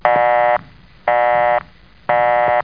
busyfone.mp3